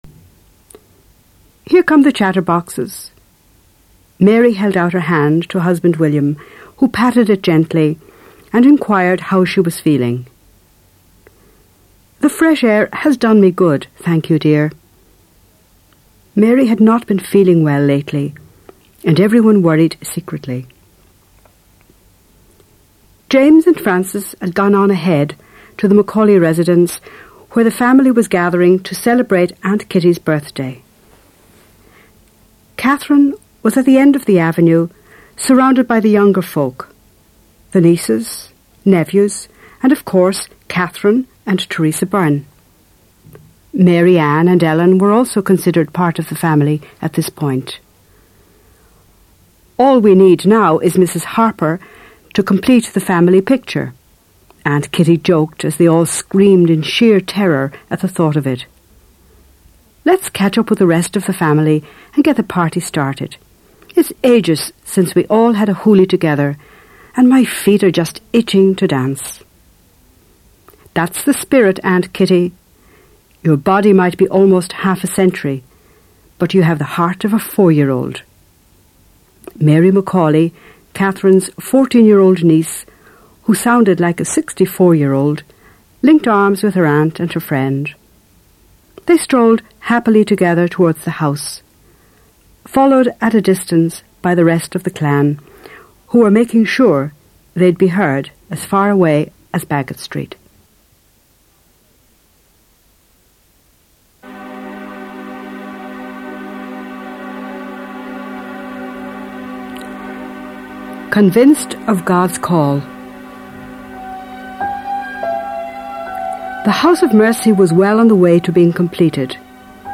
The story of Catherine McAuley for younger listeners